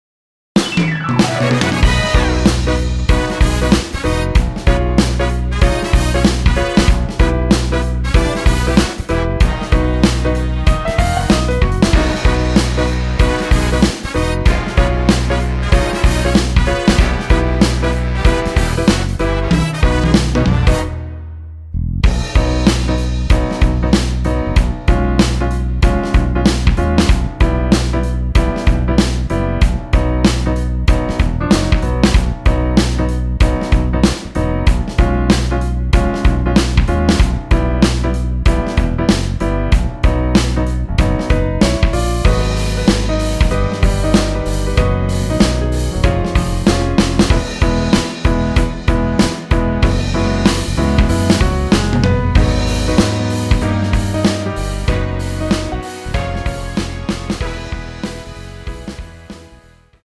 원곡보다 짧은 MR입니다.(아래 재생시간 확인)
원키에서(-3)내린 (1절앞+후렴)으로 진행되는 MR입니다.
앞부분30초, 뒷부분30초씩 편집해서 올려 드리고 있습니다.